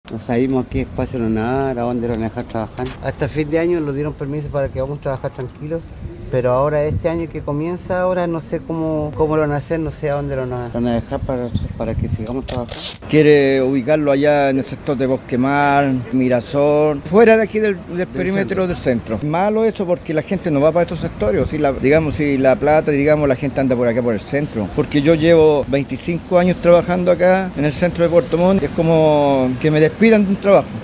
declaracion-comerciantes.mp3